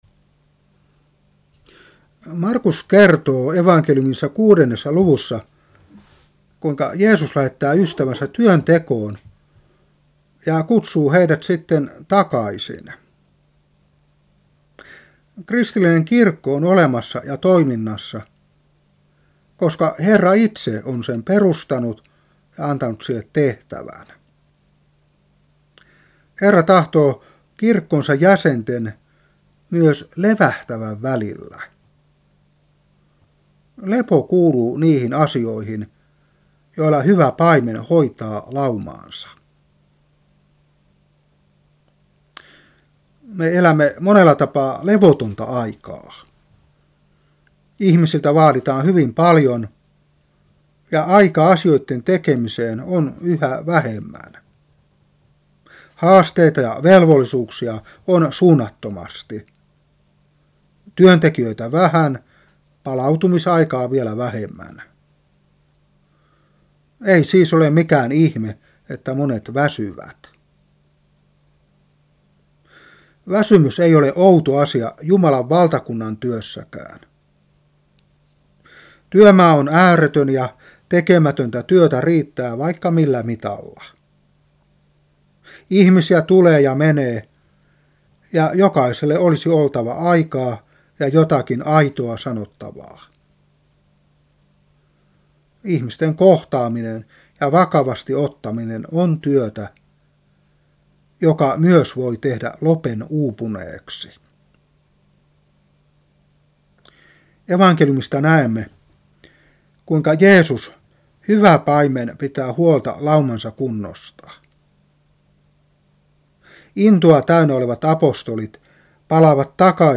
Saarna 1997-4.